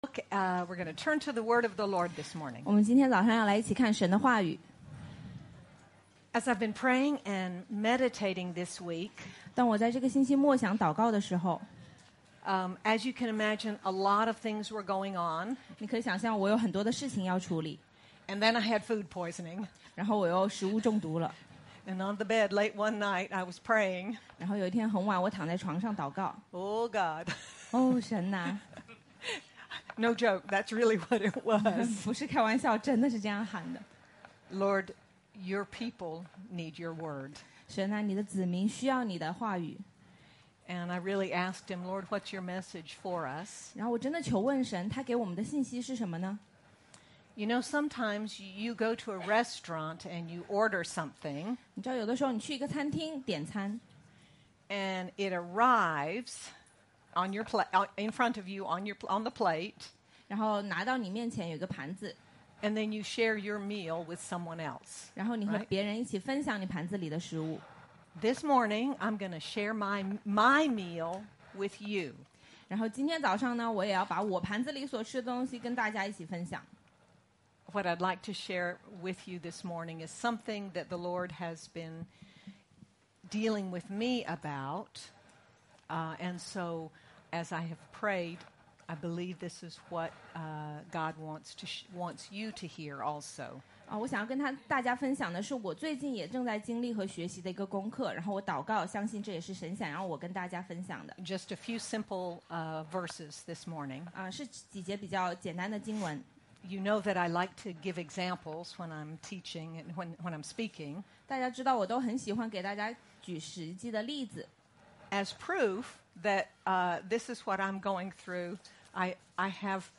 Learn how to go through trials in victory, with joy and patience in God’s generous wisdom. Sermon by